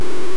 pc_struck.ogg